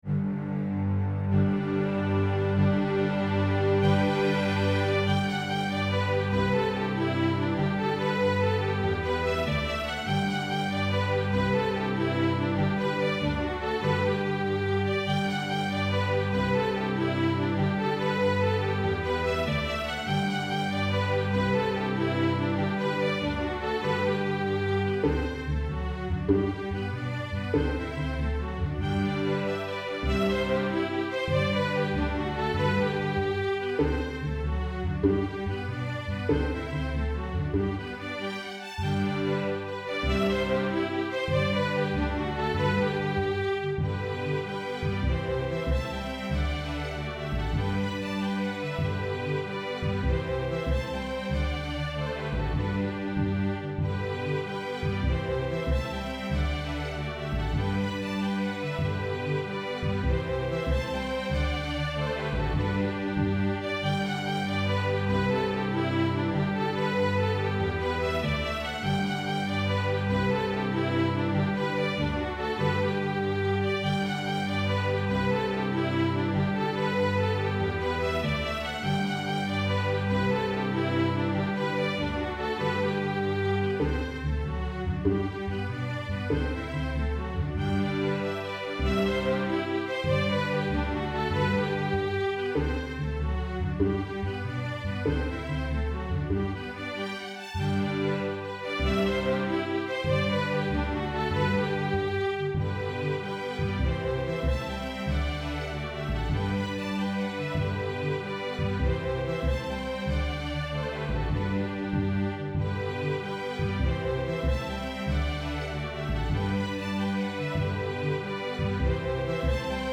INTERMEDIATE, STRING QUARTET
Notes: double stop drones, pizz, turn, grace notes, mordent
Key: G major